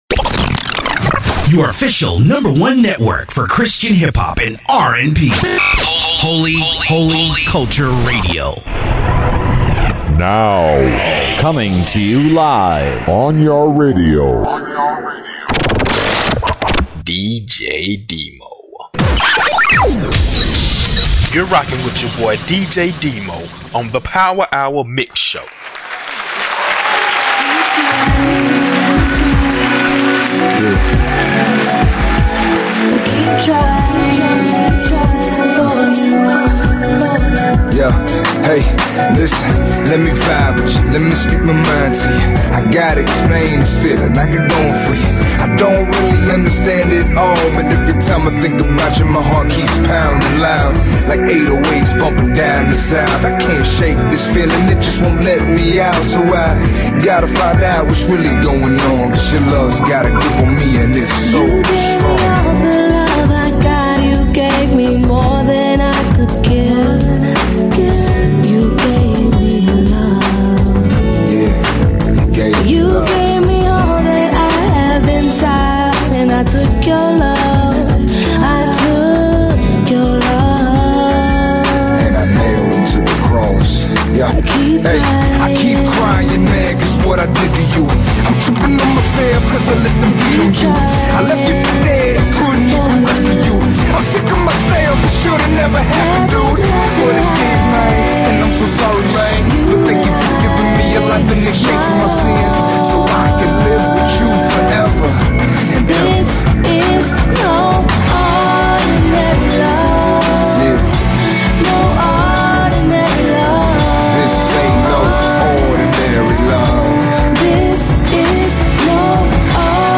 Christian Rap